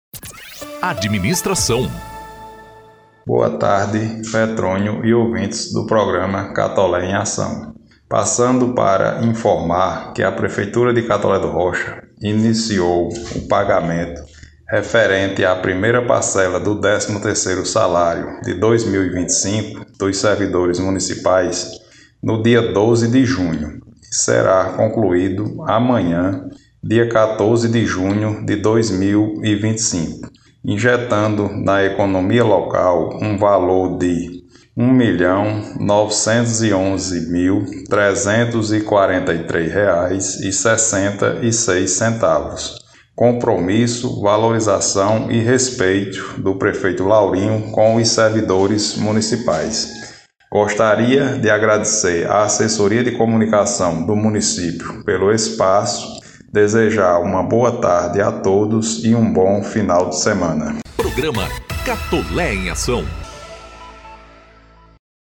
Ouça o secretário Adeildo Sá:
03-Sec.-Adeildo-Sa-Administracao.mp3